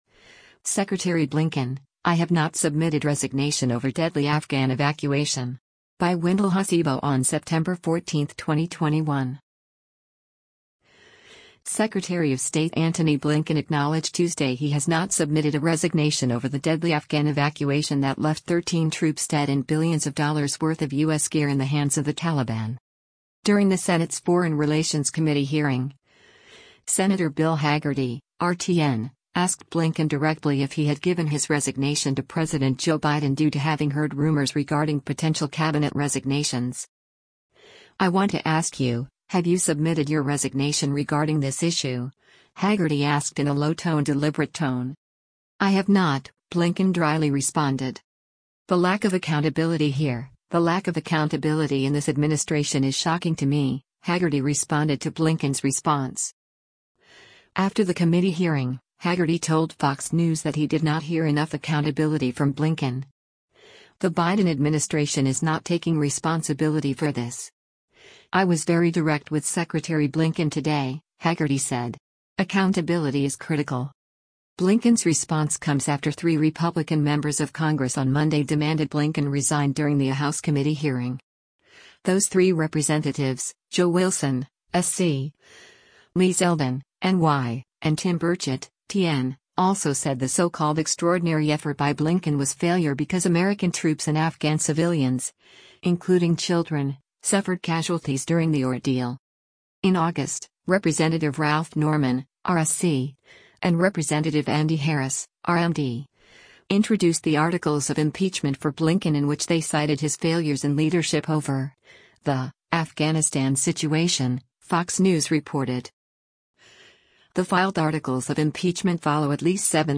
Video Source: US Senate Committee on Foreign Relations
“I want to ask you, have you submitted your resignation regarding this issue,” Hagerty asked in a low tone deliberate tone.
“I have not,” Blinken dryly responded.